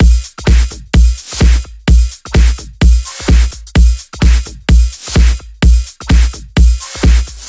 VFH1 128BPM Flatbeat Kit 1.wav